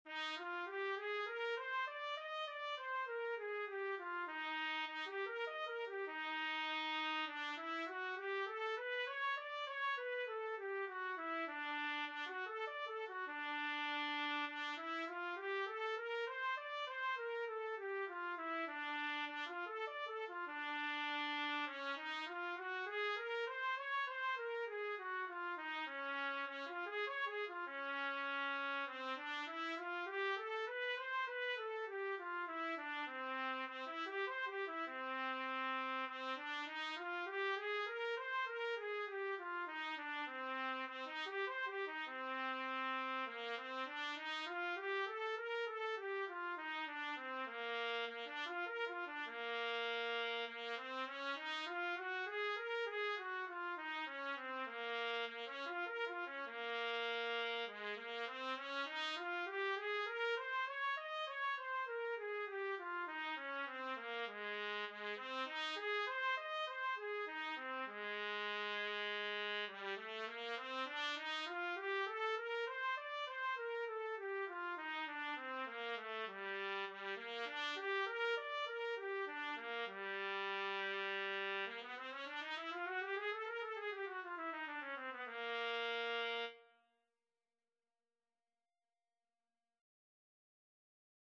Trumpet scales and arpeggios - Grade 3
trumpet_scales_grade3.mp3